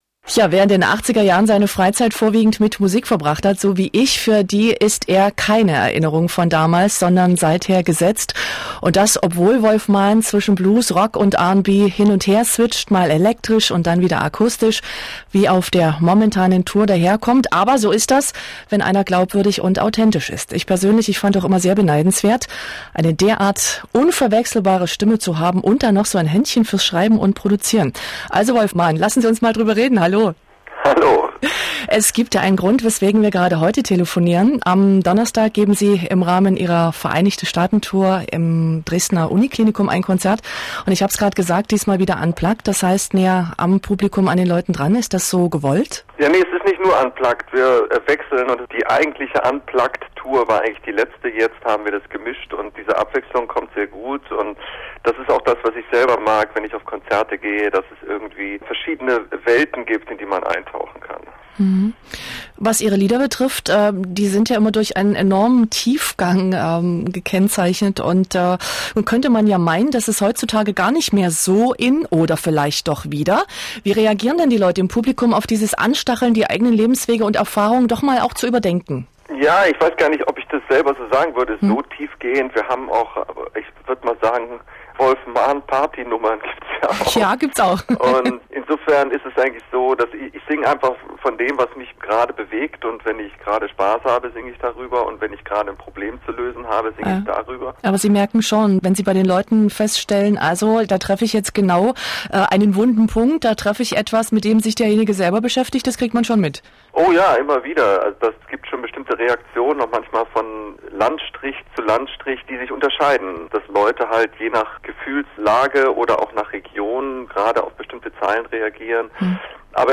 Interview Wolf Maahn MDR